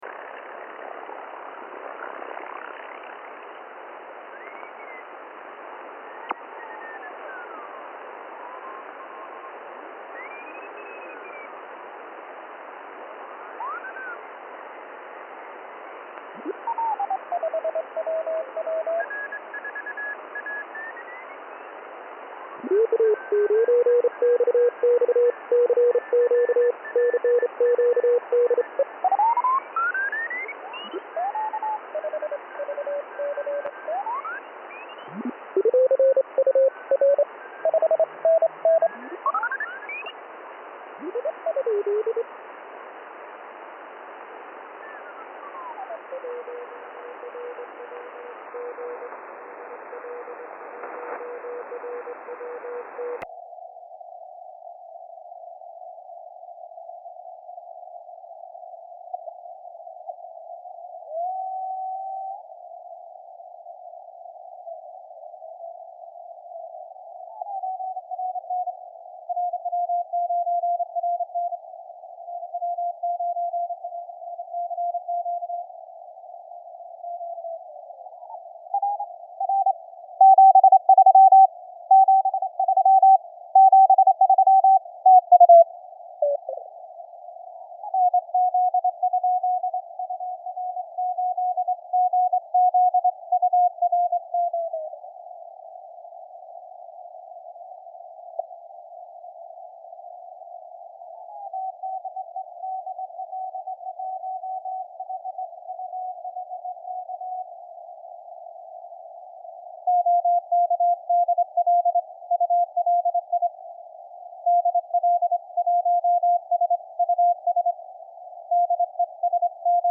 Запись производил на диапазоне 40 м, PRE отключен, RF +2.
Далее перешел в телеграфный участок. Сначала записал при широкой полосе (2.4 кГц). а потом включил CW режим:
На записи CW вроде всё чисто.